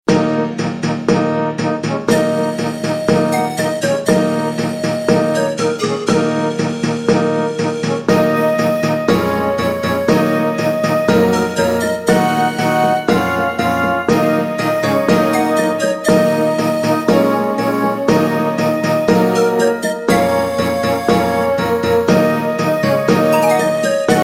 mp3 Instrumental Song Track